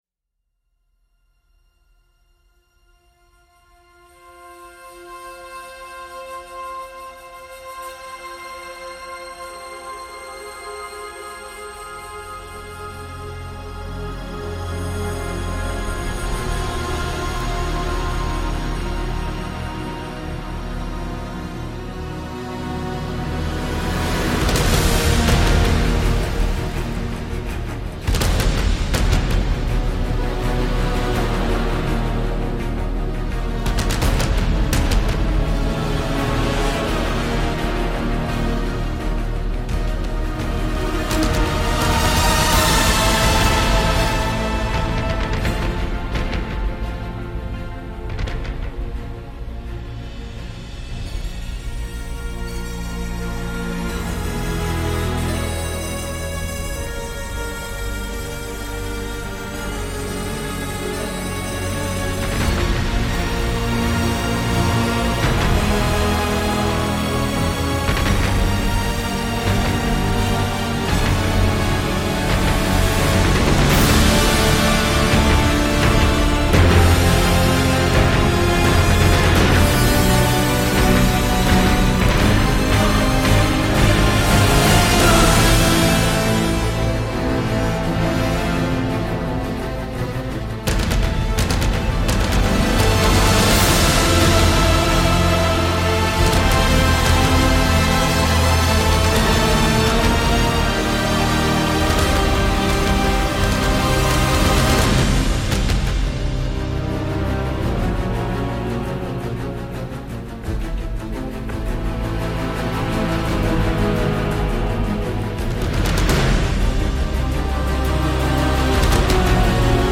Un score hybride qui forcément divisera.